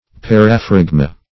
Search Result for " paraphragma" : The Collaborative International Dictionary of English v.0.48: Paraphragma \Par`a*phrag"ma\ (-fr[a^]g"m[.a]), n.; pl.
paraphragma.mp3